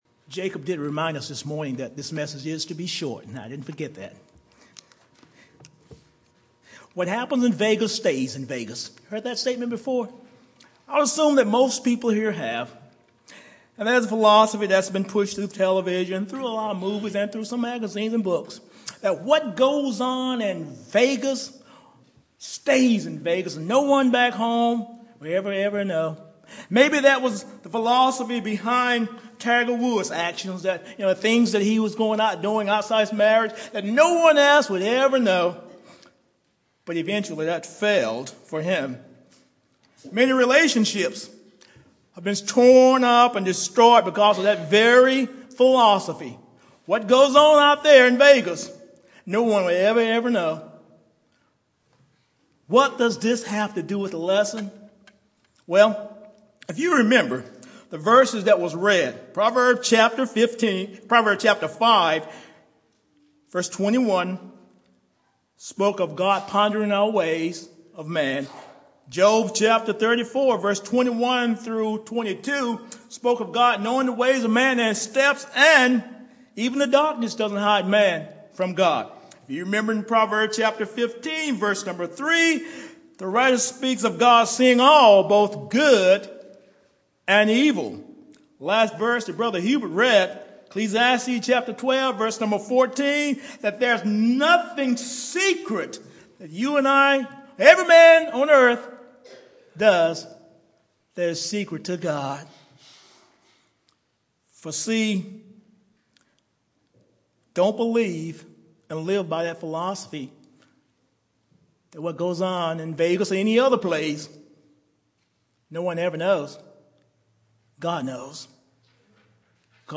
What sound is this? Categories: Sermons Tags: , , ,